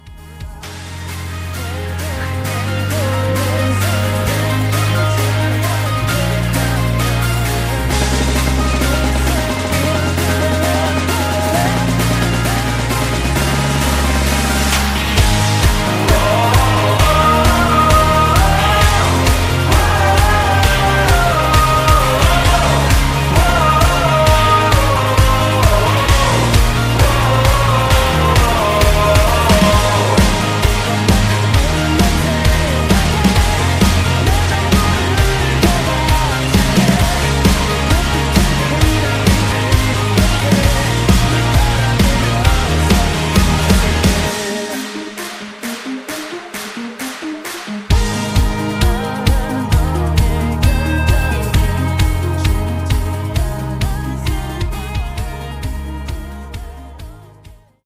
음정 -1키 3:37
장르 가요 구분 Voice MR